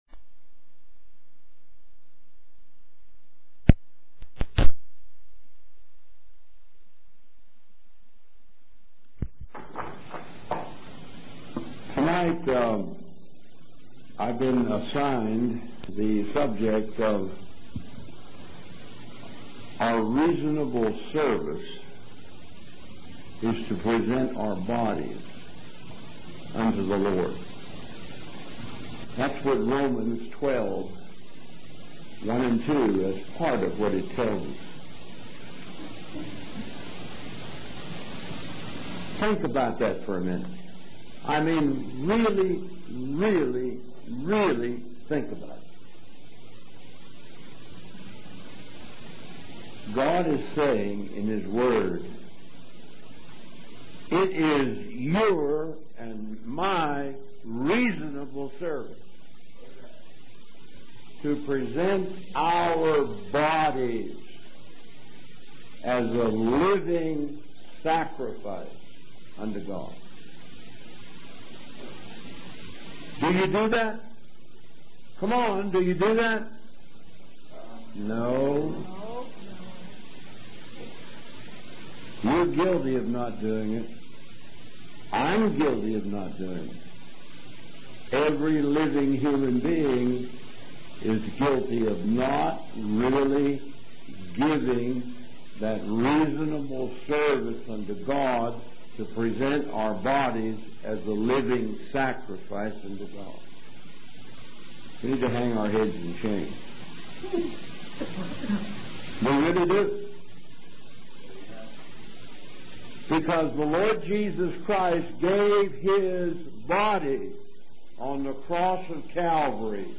Audio Messages from the 2007 Convention